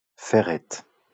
Ferrette (French pronunciation: [fɛʁɛt]